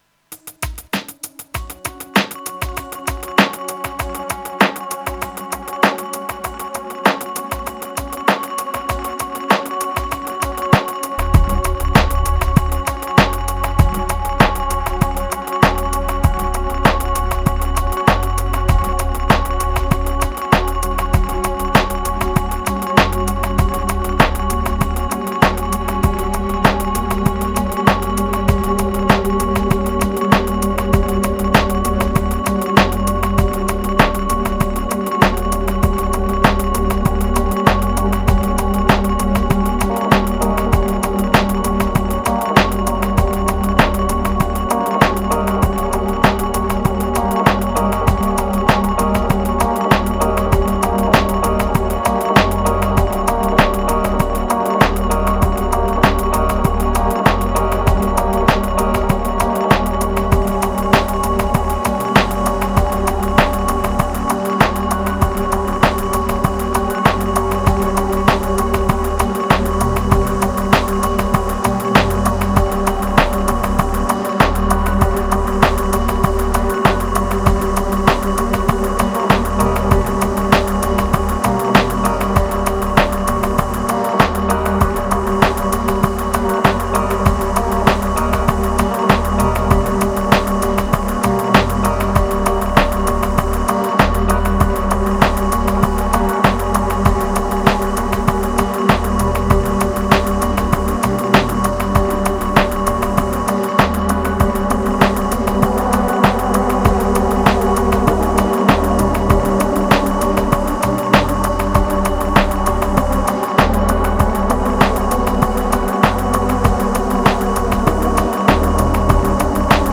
2010📈 - 94%🤔 - 98BPM🔊 - 2024-04-26📅 - 841🌟
Ambient Beats Epic Ladder Nodal Trip Pills Void Explorer